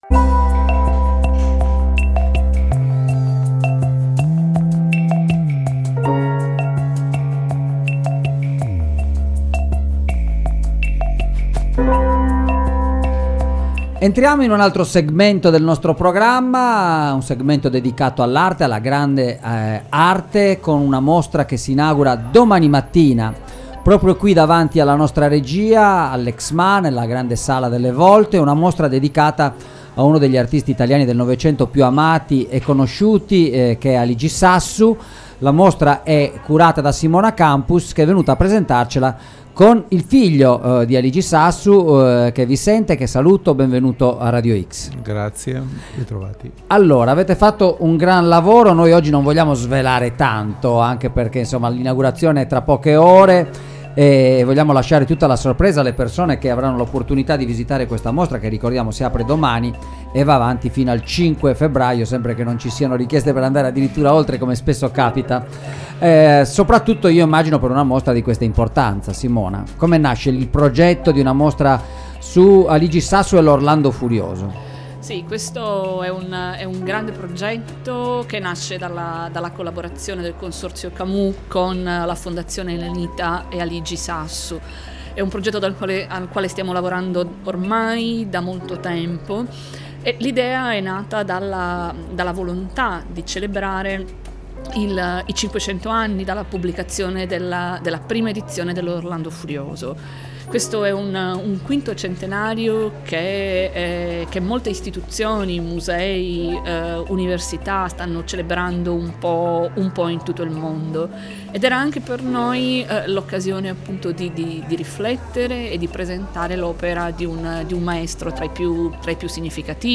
EXMA ASCOLTA L’INTERVISTA http